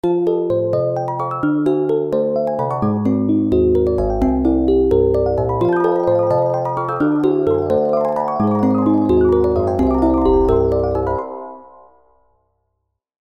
Microtonal Music
This is just a nice little ring tone I wrote to commemorate a new cell phone, which supports MP3 ring tones.